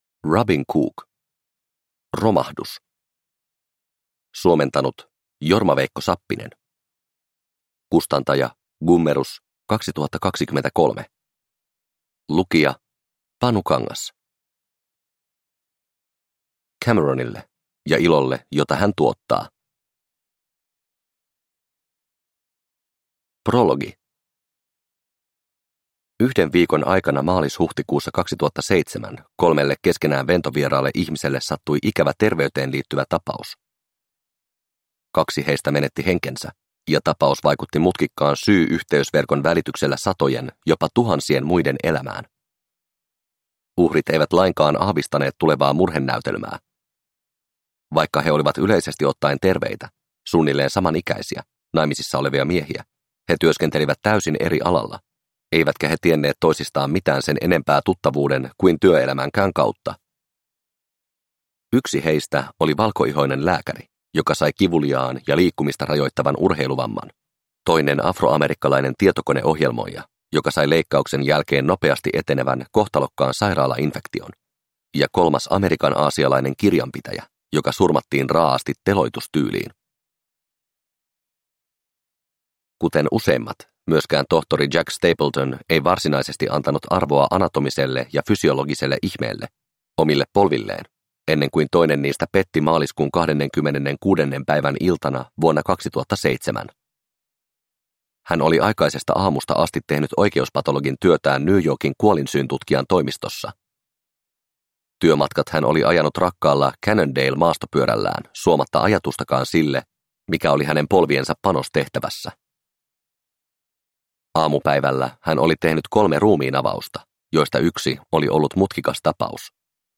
Romahdus – Ljudbok – Laddas ner